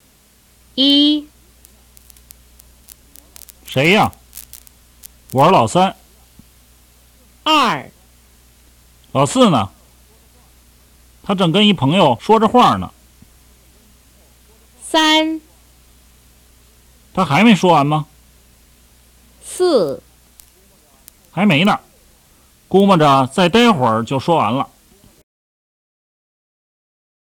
In each of the audio files below, the speaker will say the following, at least how they would say the same thing in their dialect.
1. Běijīng Dialect (Northern Mandarin)
01-beijing-hua.m4a